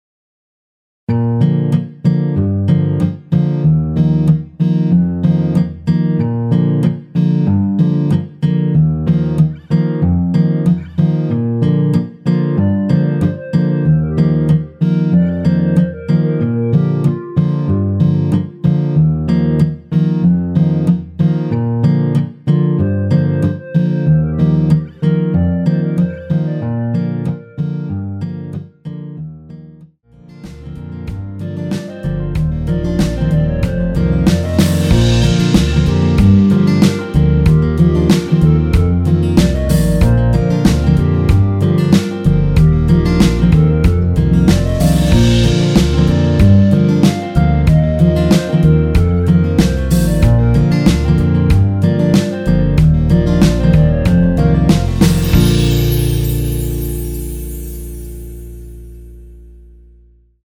원키에서(-1)내린 멜로디 포함된 MR입니다.
Bb
앞부분30초, 뒷부분30초씩 편집해서 올려 드리고 있습니다.